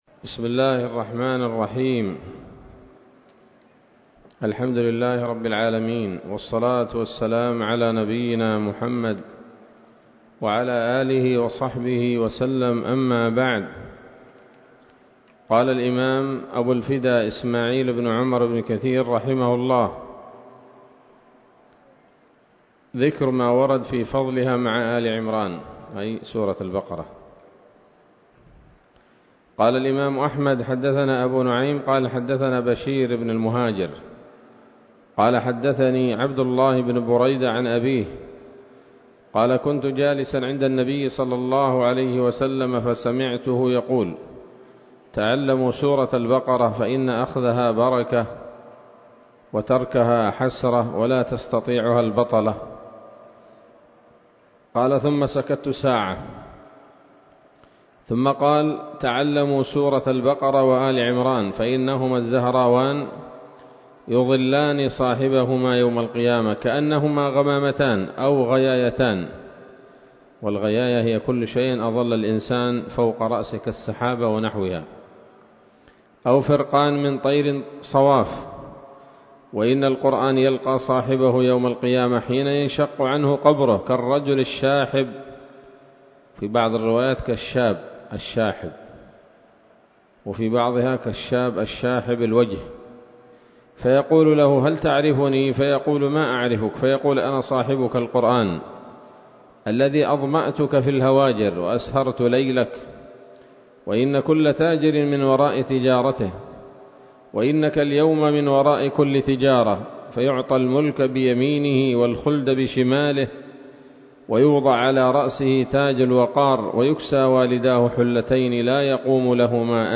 الدرس الثاني من سورة البقرة من تفسير ابن كثير رحمه الله تعالى